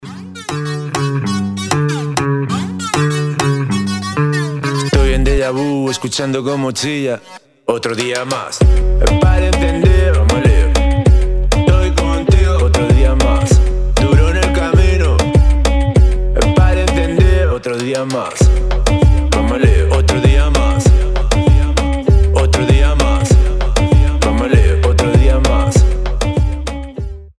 • Качество: 320, Stereo
ритмичные
Хип-хоп
заводные
качающие
Moombahton